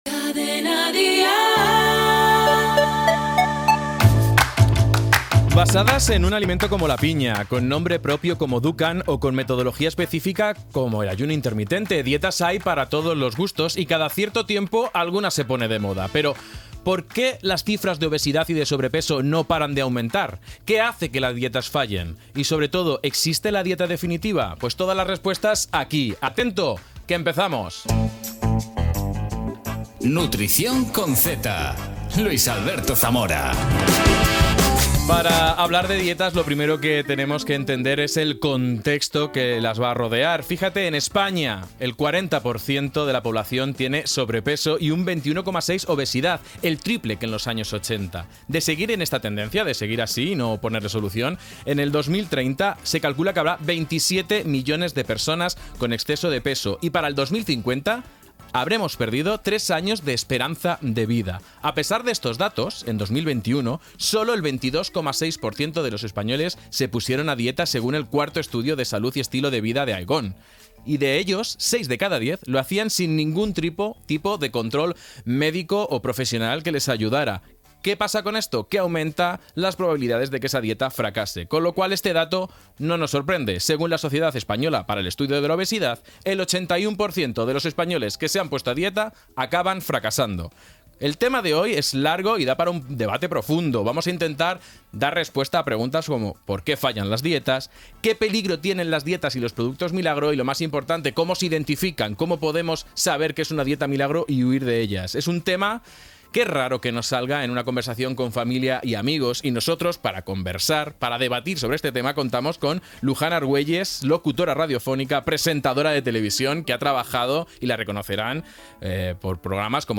Indicatiu de la cadena, dades sobre l'obessitat a l'Estat espanyol i les dietes, presentació i entrevista a la presentadora Luján Argüelles per tractar del tema de les dietes alimentàries i del requisit social d'estar prims Gènere radiofònic Divulgació